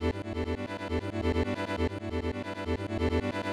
• techno synth sequence inspiration 136 7.wav
techno_synth_sequence_inspiration_136_7_Jag.wav